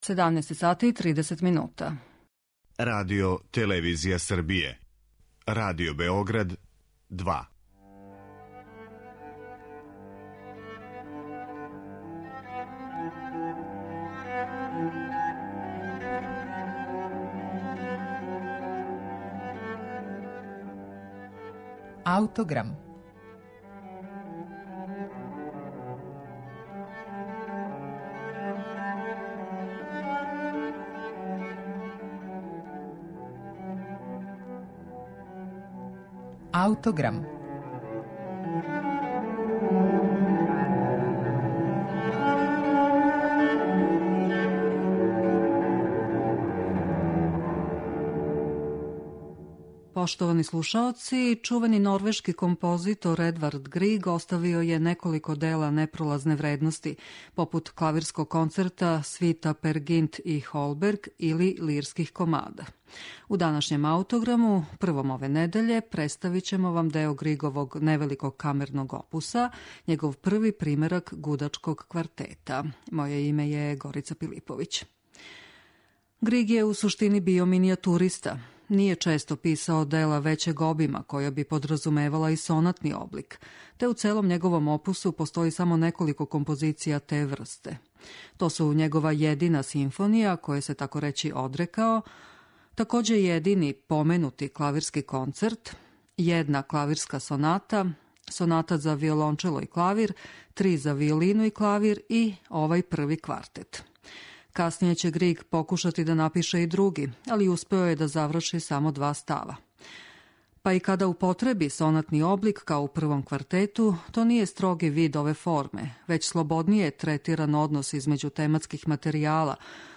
Едвард Григ: Први гудачки квартет